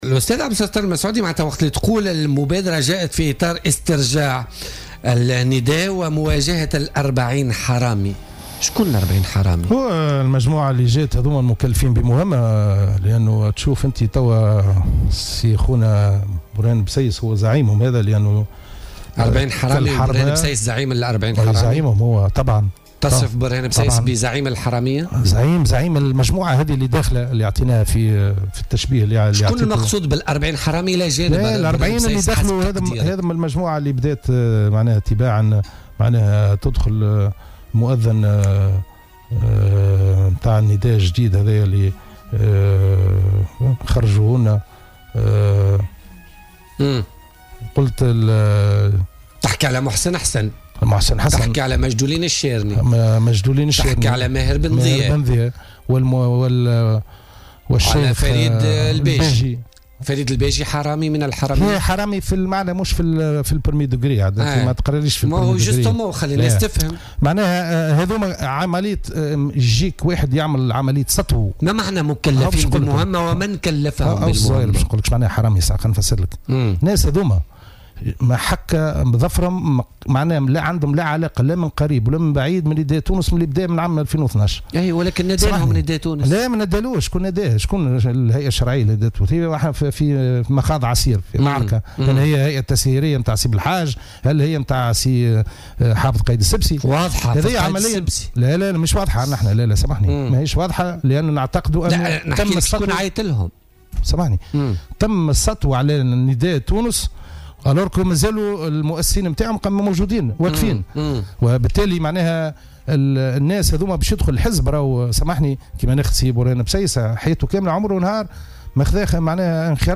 ووجه ضيف بوليتيكا على "الجوهرة أف أم" انتقادات لاذعة للإعلامي والناشط السياسي برهان بسيس المكلف مؤخرا بخطة الملف السياسي بنداء تونس، معلقا بالقول بأنه مجرّد "مرتزق مكلف بمهمة بمقابل"، مؤكدا انه لا علاقة له بنداء تونس منذ تأسيسه منذ 2012 ولا دخل له بالسياسة أصلا. وأضاف أن هناك محاولة سطو على نداء تونس عبر هؤلاء وبحضور مؤسسيه وقيادييه.